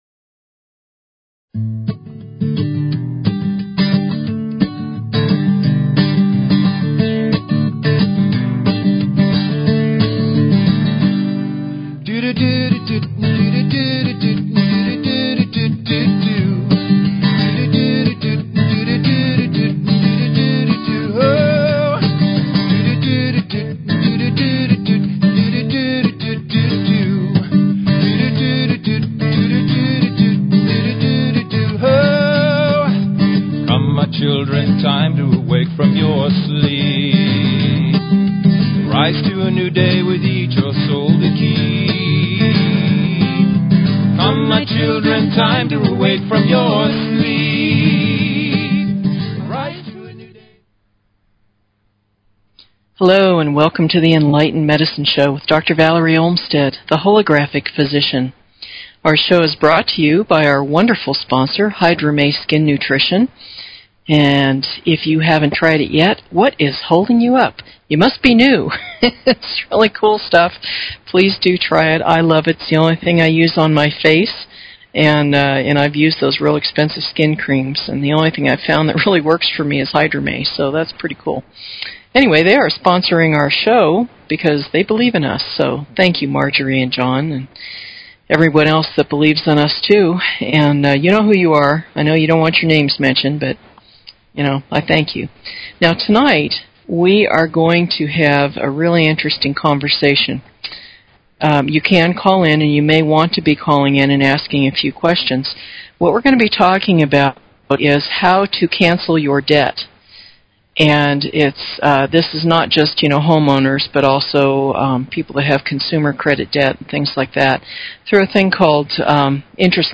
Talk Show Episode, Audio Podcast, Enlightened_Medicine and Courtesy of BBS Radio on , show guests , about , categorized as
We will take calls and have a great time...and bring hope to a lot of people!